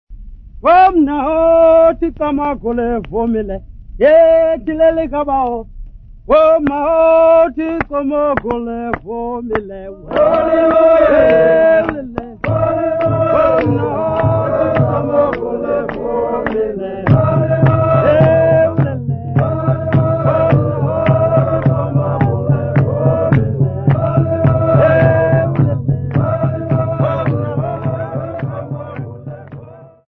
Amampondo locals
Folk music
Sacred music
Field recordings
Africa South Africa Langa, Cape Town sa
Traditional Xhosa song with singingand clapping accompanied by drumming